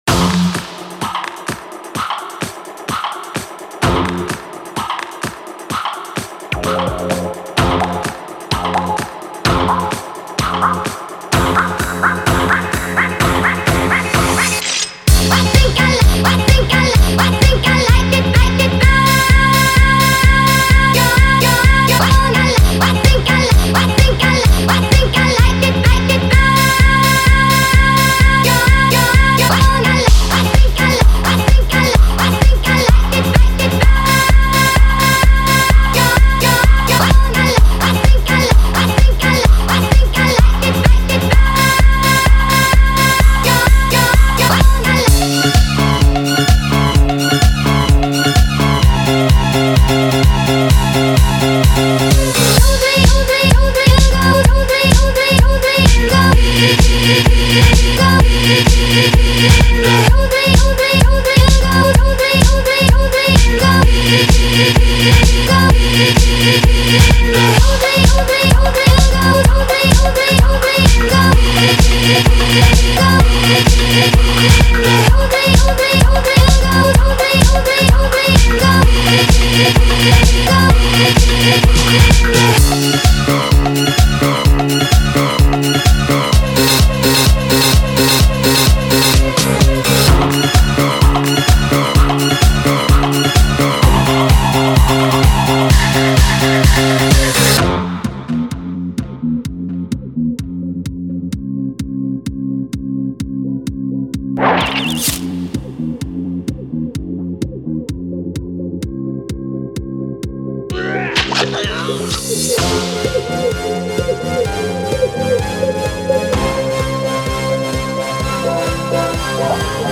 This has a really fun beat to it.
I think it’s hectic.